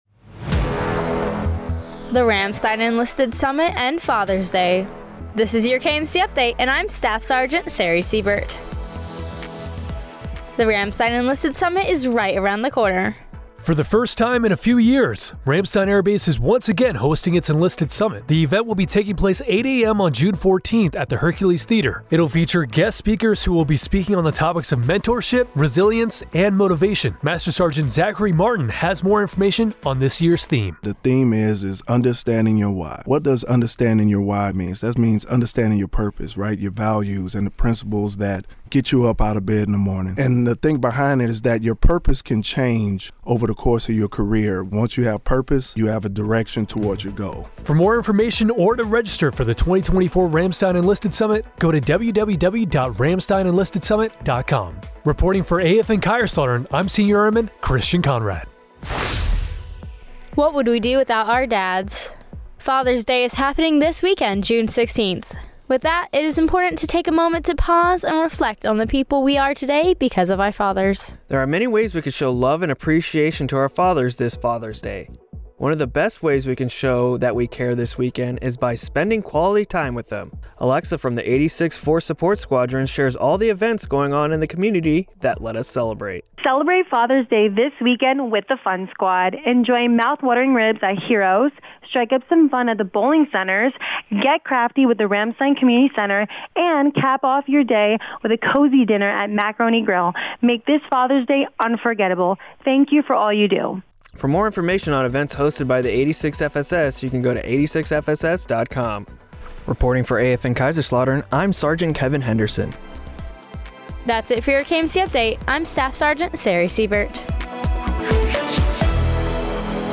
Fathers DayKMC UpdateRamstein Enlisted SummitAFN KaiserslatuernAFN